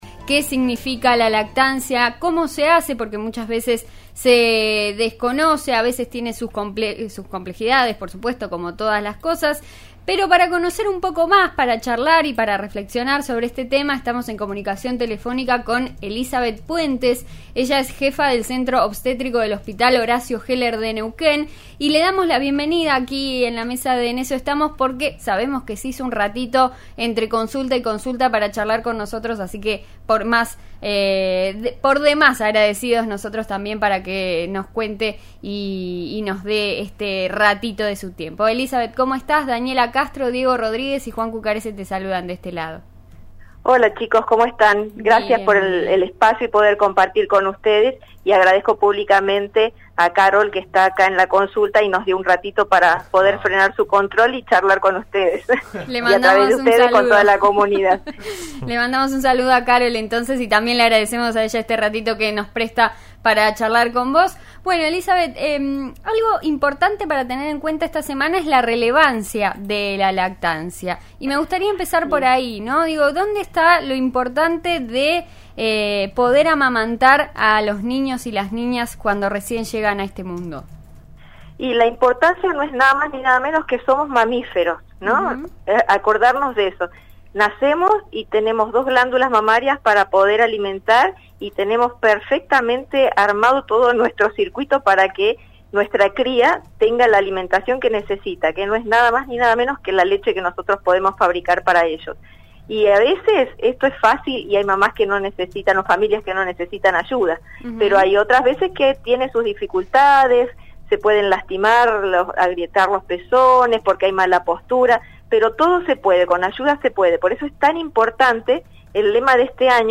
La entrevista se dio en el marco de la semana de la lactancia materna.